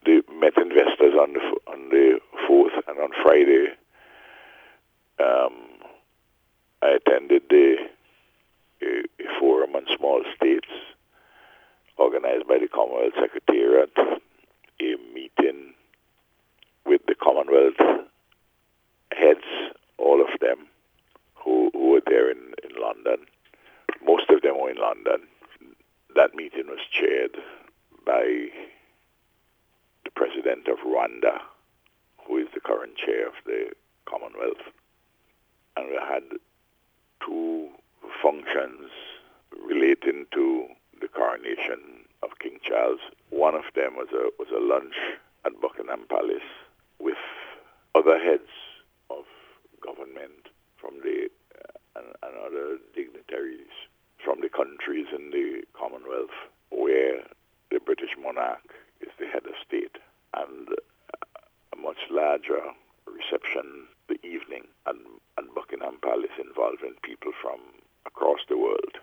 Speaking with NBC News last week the Prime Minister explained that he had meetings with several leaders of the European Union.